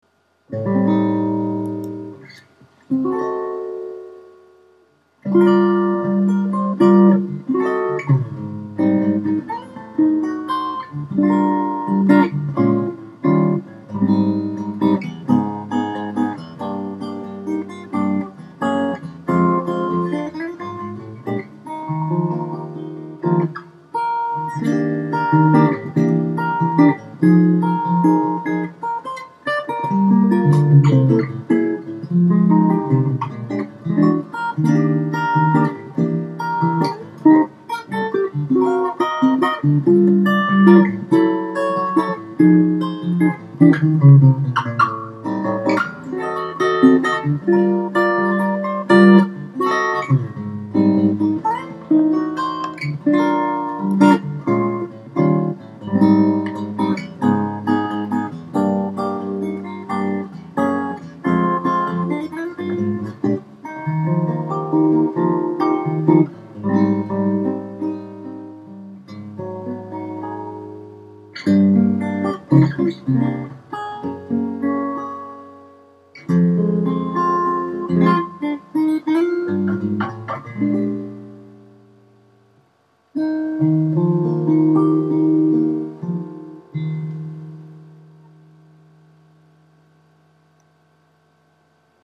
■ アコースティックギター（ピックアップマイクブレンド）
4. マグネットマイク＋楽器用コンデンサーマイク
フィンガーピッキングなど、繊細でやわらかめのサウンド表現に適した組み合わせです。
とげとげしさのない温かいサウンドは、誰の耳にも馴染みやすく、ホームパーティーや演奏会などでも、おすすめの組み合わせです。